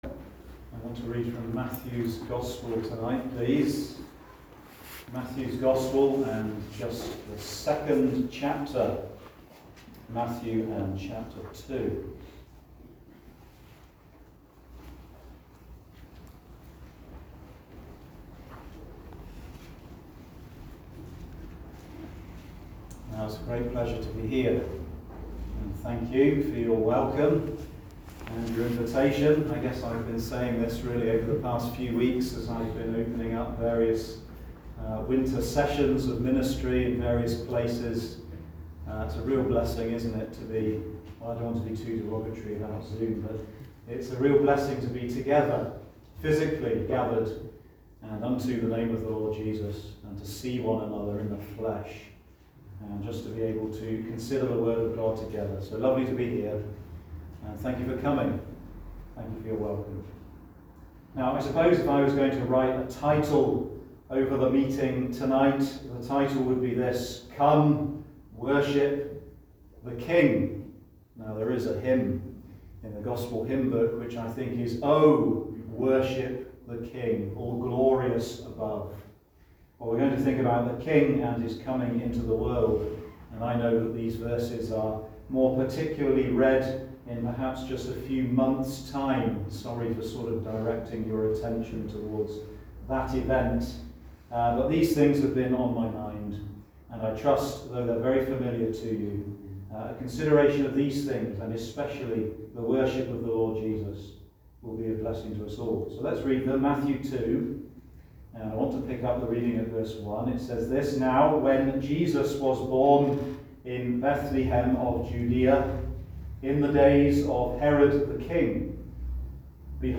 1 Corinthians 11:3 Service Type: Ministry